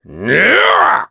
Kaulah-Vox_Attack3_jp.wav